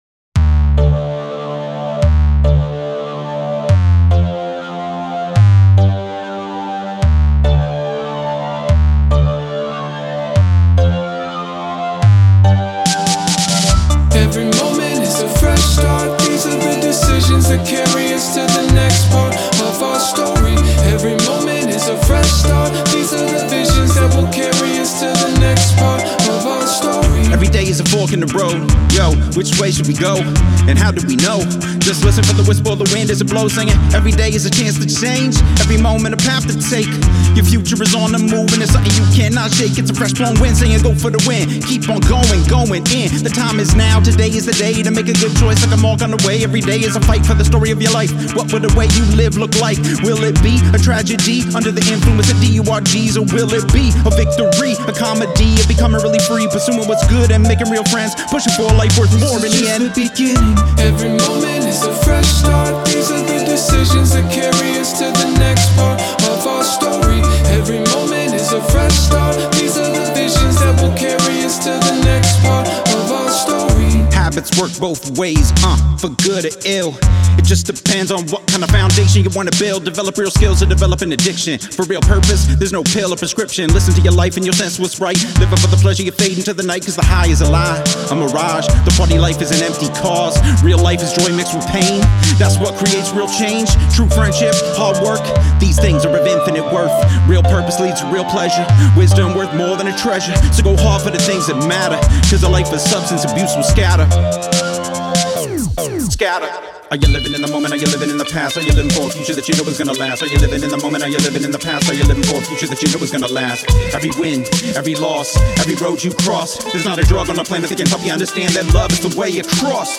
fun, high energy hyper pop song